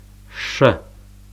ʃʰa шʰэ ɕa щэ ʃa шэ